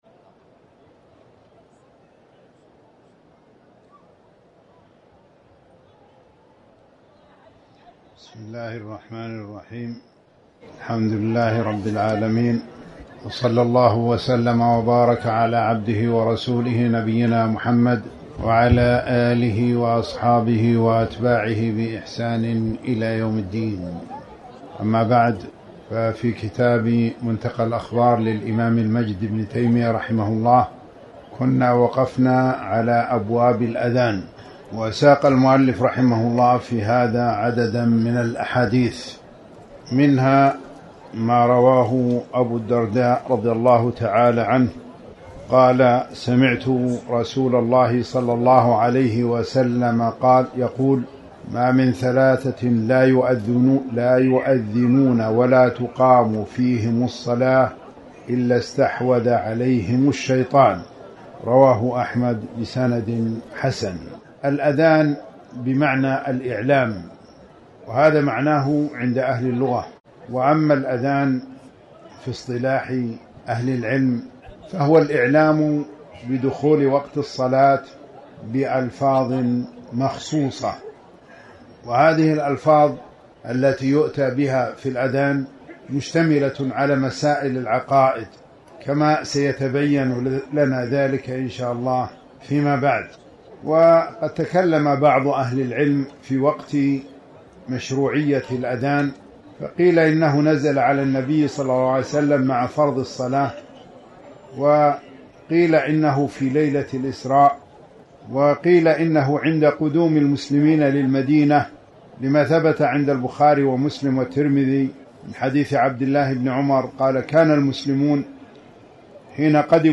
تاريخ النشر ٧ صفر ١٤٤٠ هـ المكان: المسجد الحرام الشيخ